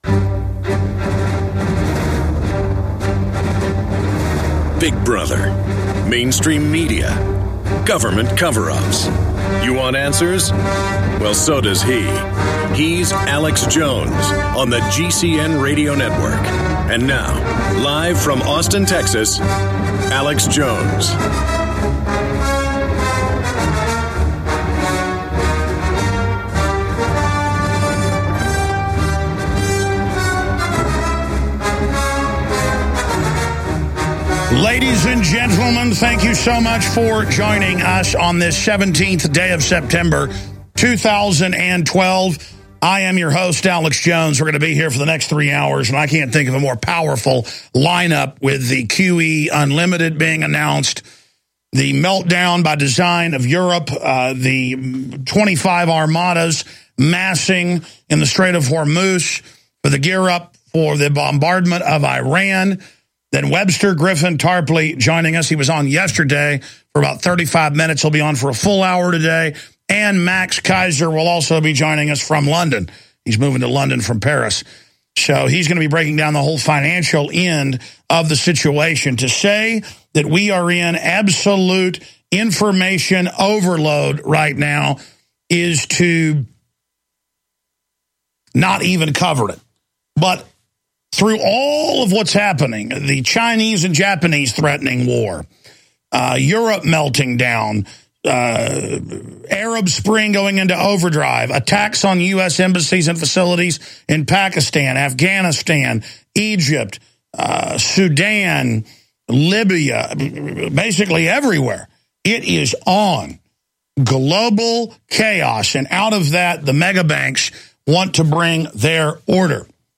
Watch Alex's live TV/Radio broadcast.